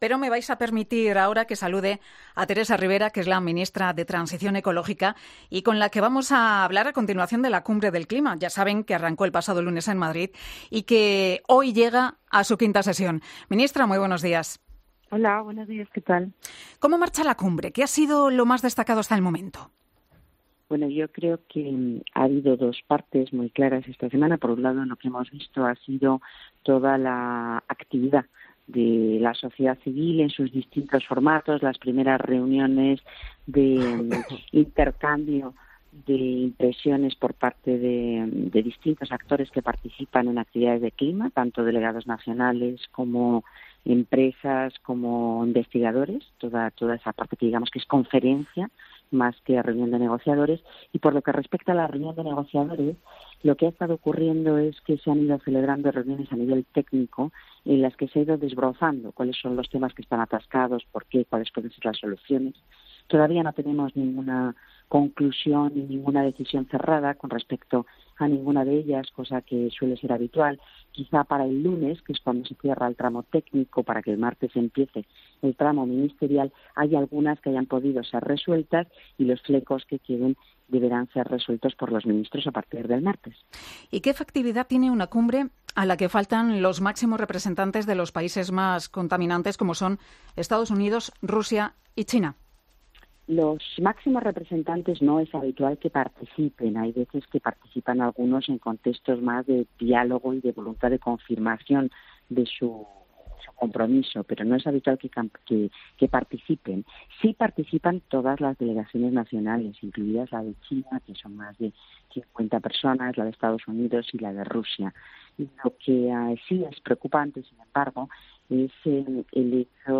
Teresa Ribera , la ministra de Transición Ecológica en funciones, ha sido entrevistada este viernes en 'Herrera en COPE' a propósito de la Cumbre del Clima que se está celebrando en Madrid.